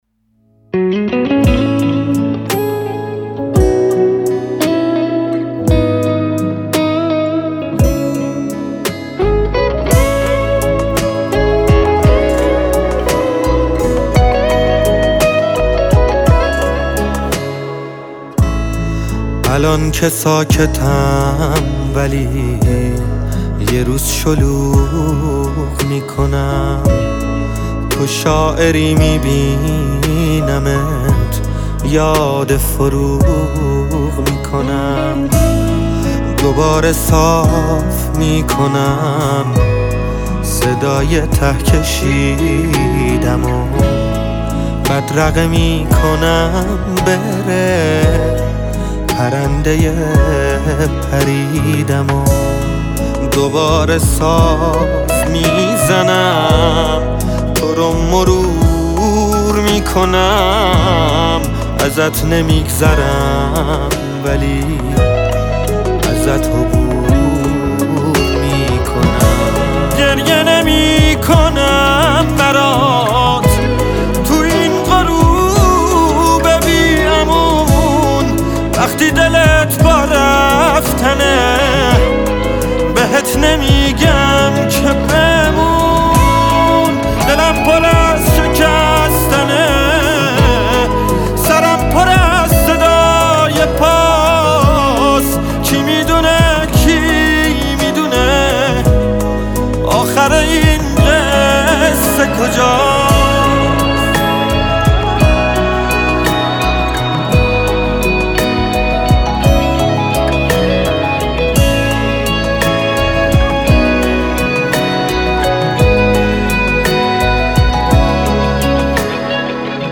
ترکیبی از یه حال و هوای عاشقانه و چس ناله ای هست